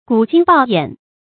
鼓睛暴眼 注音： ㄍㄨˇ ㄐㄧㄥ ㄅㄠˋ ㄧㄢˇ 讀音讀法： 意思解釋： 眼睛突出的樣子。